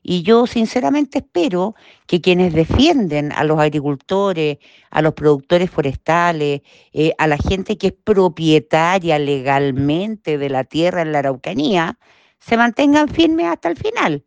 A su vez, la diputada, Gloria Naveillán, espera que la Comisión no se olvide de quienes tienen propiedades en La Araucanía.